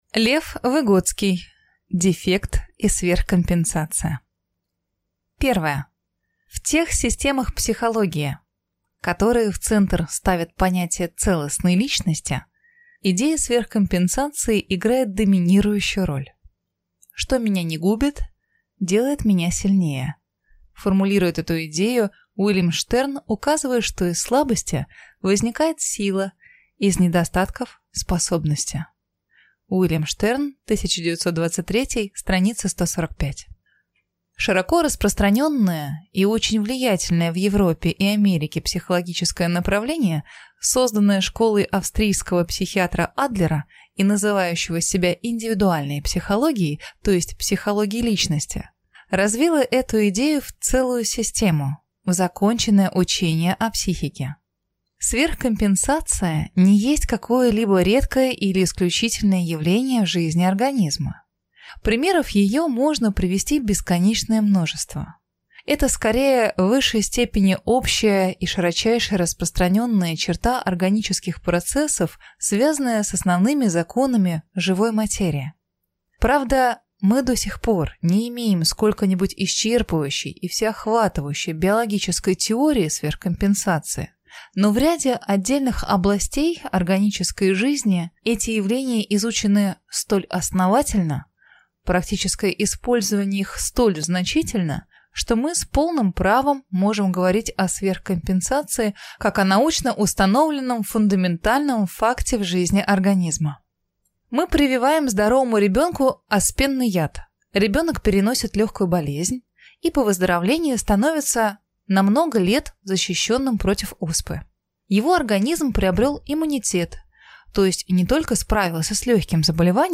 Аудиокнига Дефект и сверхкомпенсация | Библиотека аудиокниг